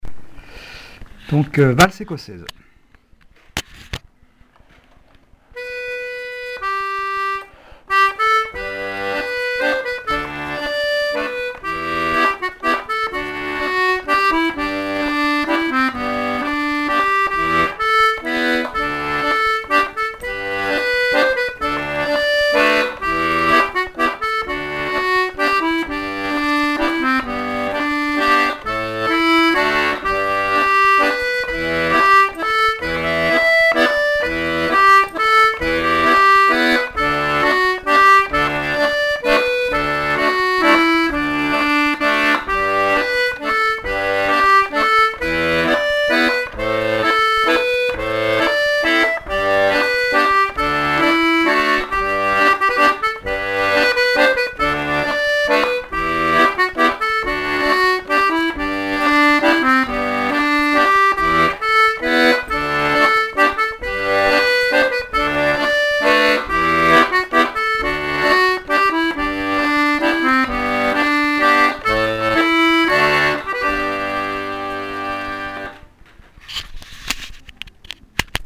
l'atelier d'accordéon diatonique
4) Valse écossaise
valse ecossaise.mp3